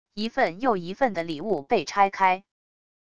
一份又一份的礼物被拆开wav音频